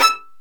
Index of /90_sSampleCDs/Roland L-CD702/VOL-1/STR_Violin 4 nv/STR_Vln4 % + dyn
STR VLN JE1P.wav